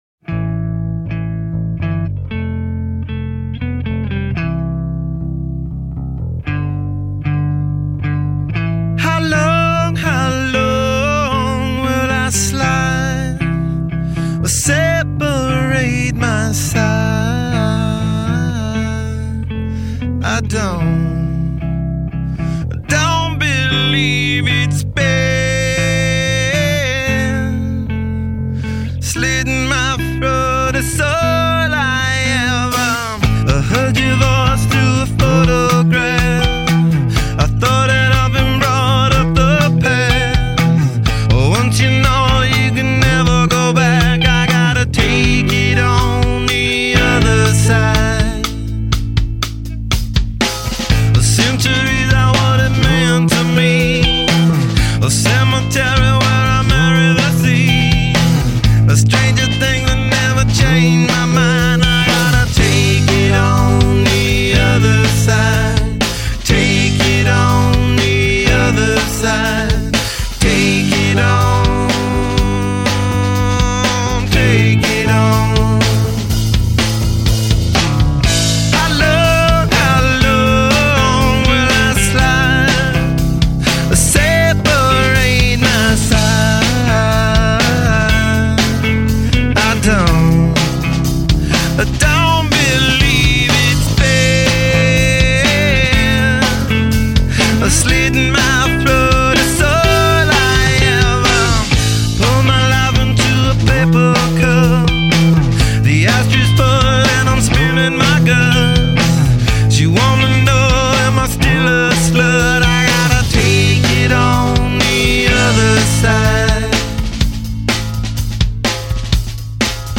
Жанр: Alternative